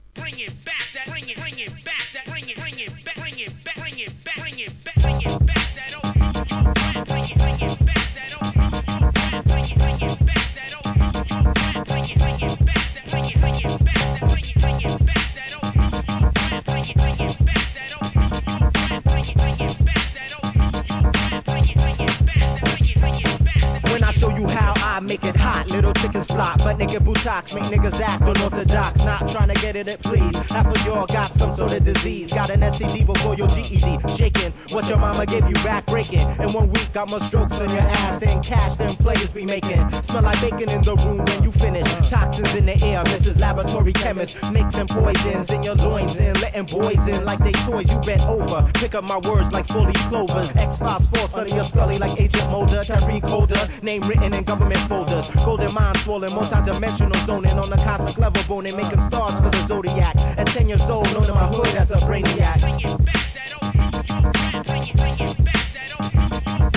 HIP HOP/R&B
'00s良質アンダーグランド!!!